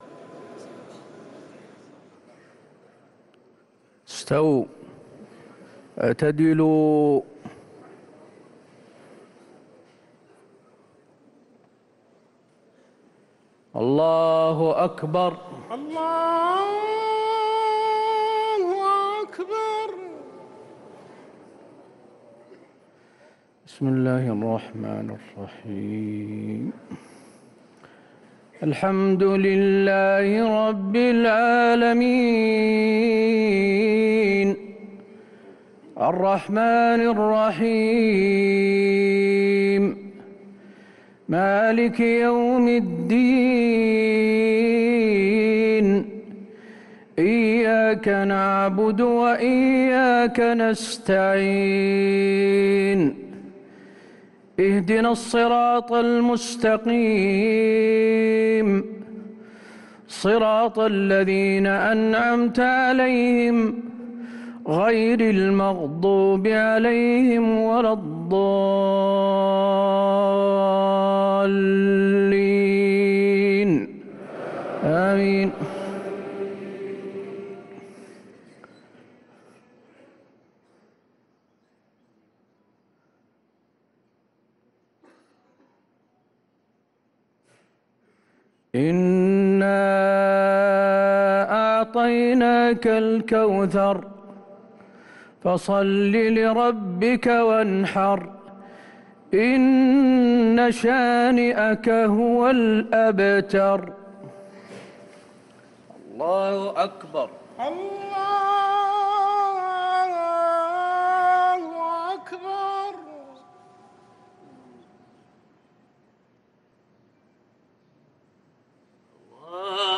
صلاة العشاء للقارئ حسين آل الشيخ 20 رمضان 1444 هـ